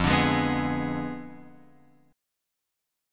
Fdim7 chord